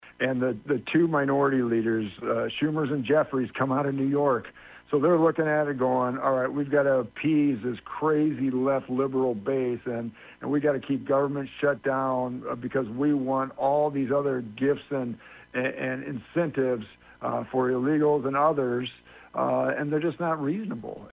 FEENSTRA TALKS ABOUT SHUTDOWN EFFECTS